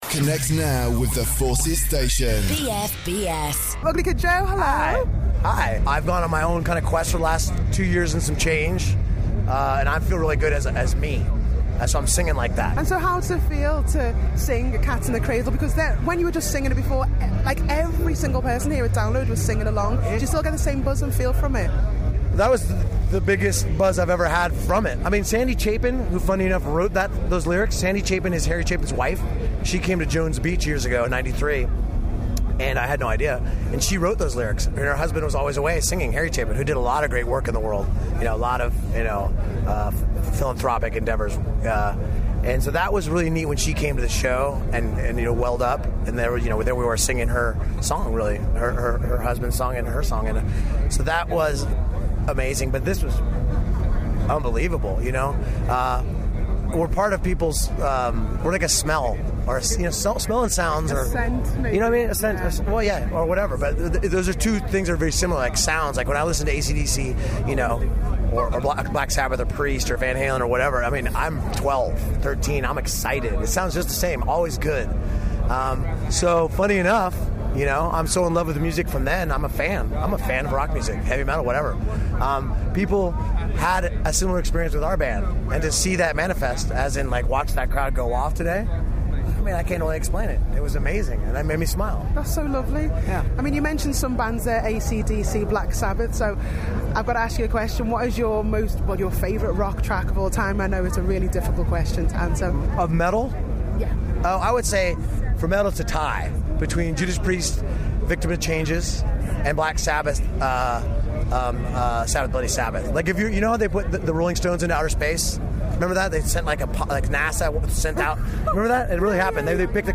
Ugly Kid Joe talk to BFBS Rocks